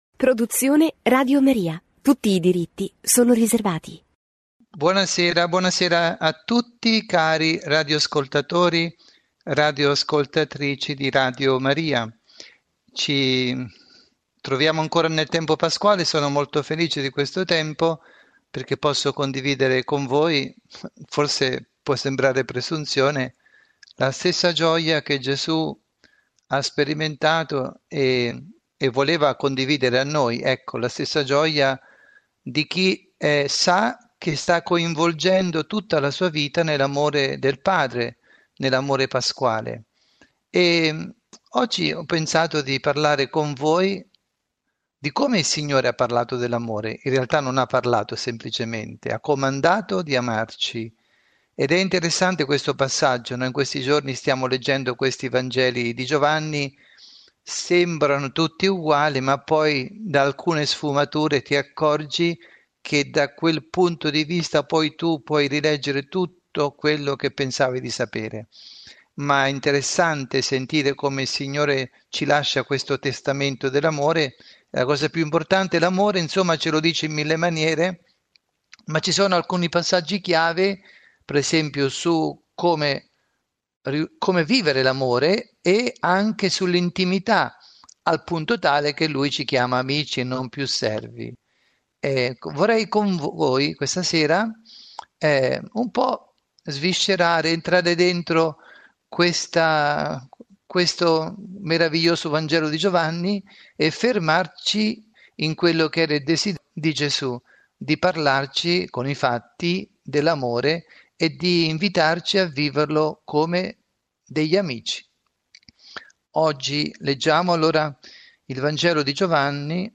Catechesi
trasmessa in diretta su Radio Maria